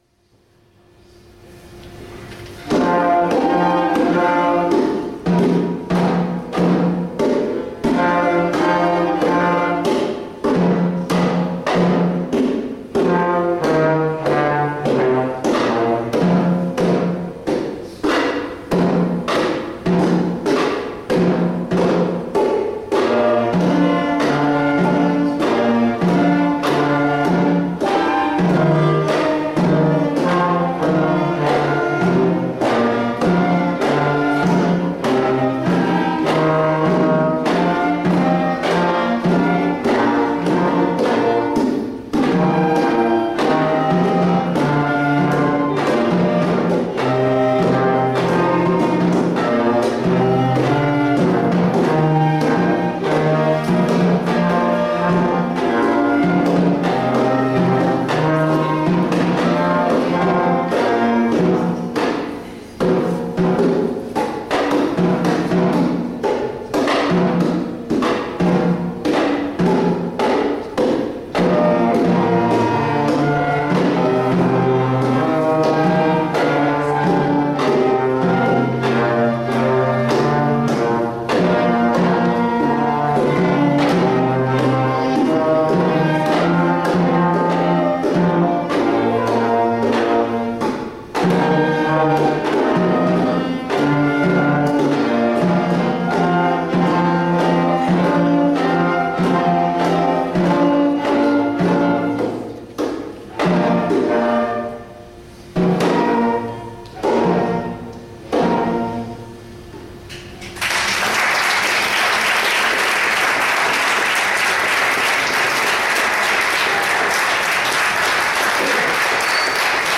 Banda de música de manzanares el real
Para celebrar la Navidad ralizamos nuestro concierto en la sala de plenos del Ayuntamiento,.